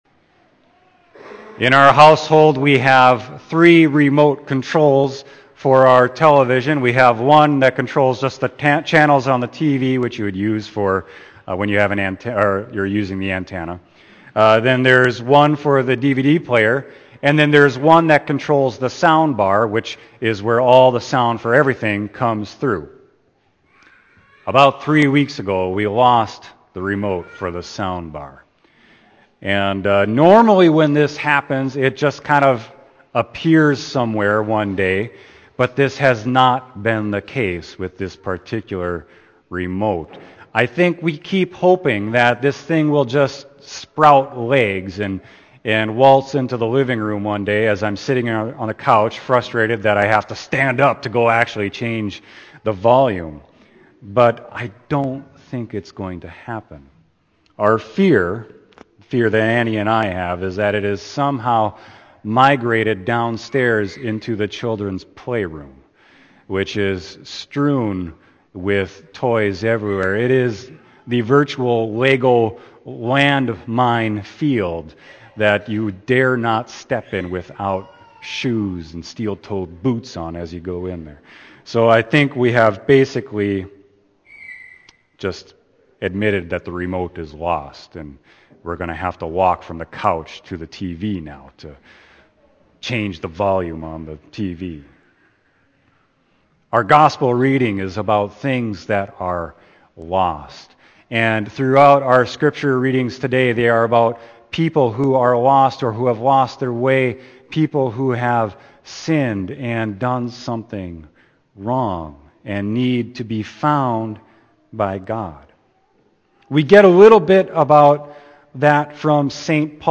Sermon: Luke 15:1-10